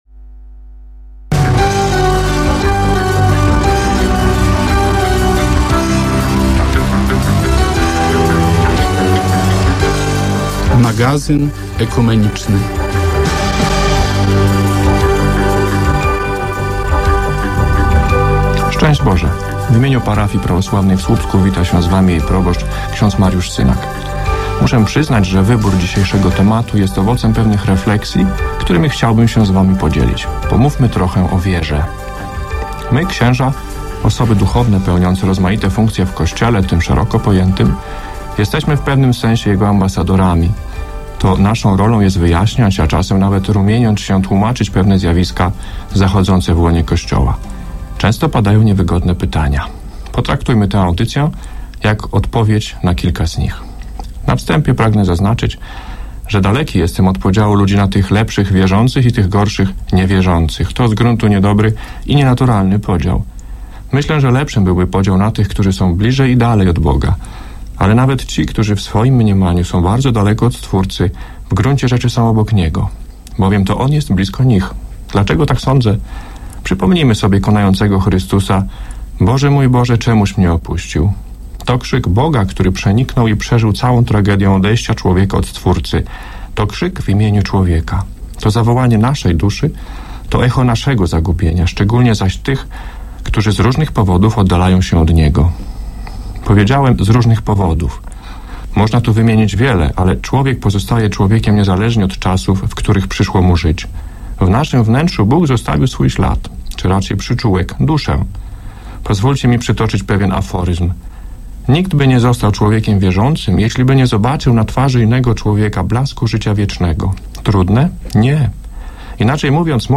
Audycja